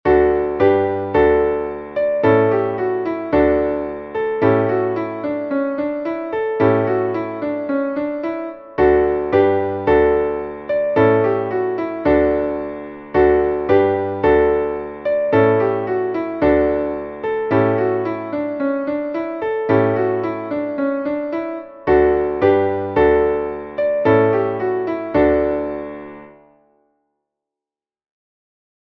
Traditionelles Winterlied